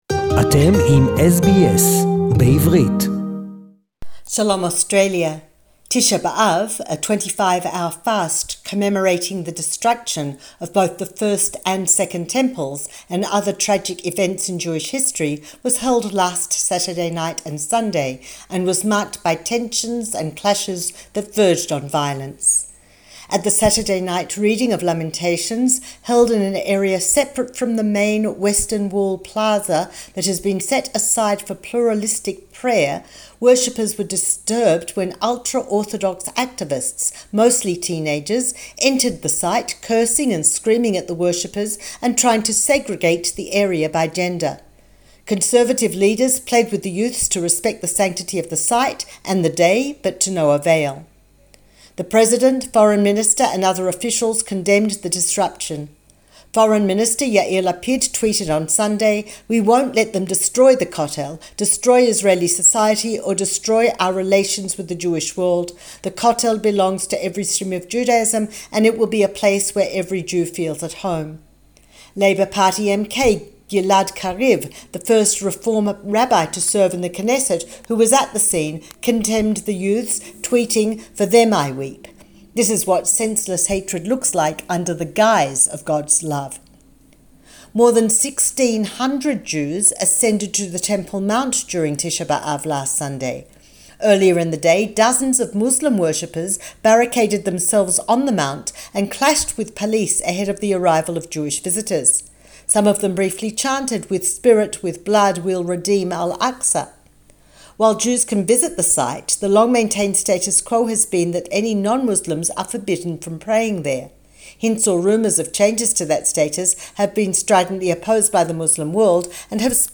Israel's Olympic medal...& more news...SBS Jerusalem report in English